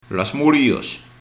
Prononcer "Las Moulïos", "Las Moulïes", "Las Moulis"...